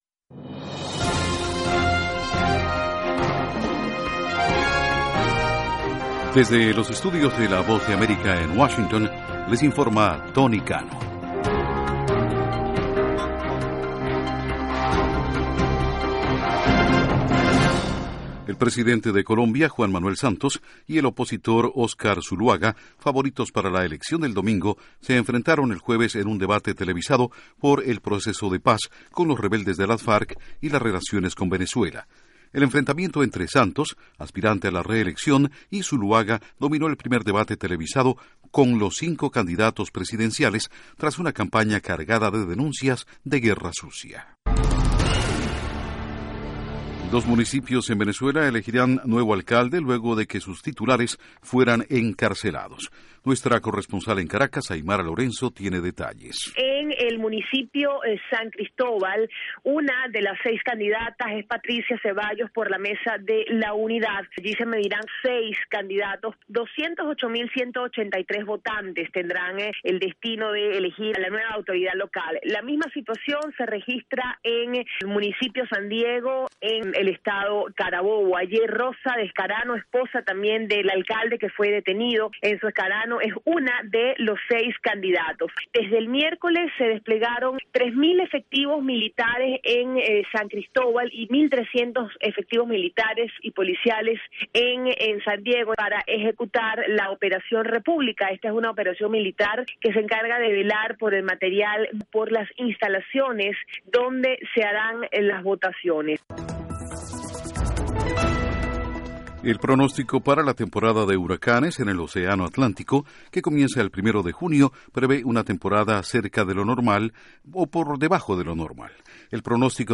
Resumen de noticias de La Voz de América